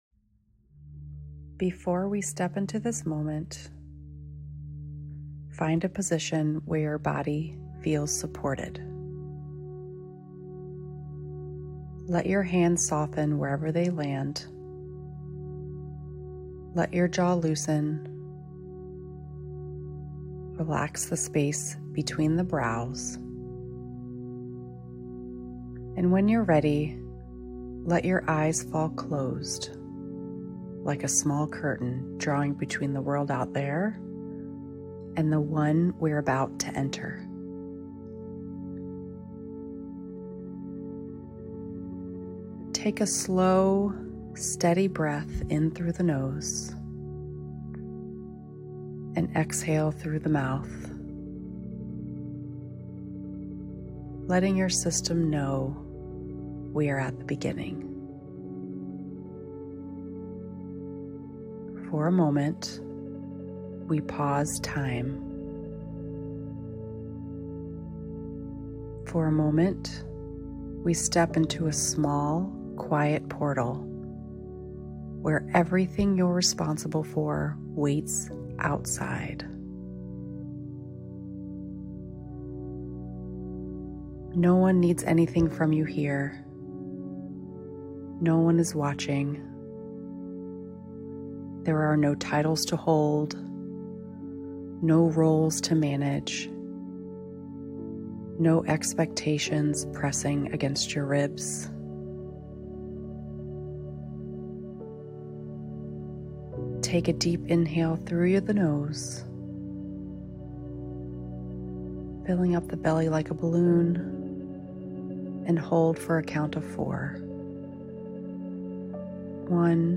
A guided meditation for presence that helps you step out of roles and pressure and return to yourself—steady, grounded, and fully here.